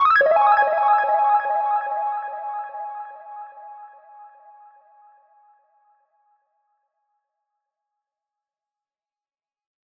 Bounce Driven Hihat Patterns For Instant Rhythmic Inspiration
Professionally Recorded Guitar Loops Designed For Trap, RnB, Pop & More
Featuring an assortment of Cinematic Style Impacts, Arps, Reverse FX, and Various FX, each sound inside works great for any style of track.
Textural Percussion Loops For Adding Movement & Character To Your Music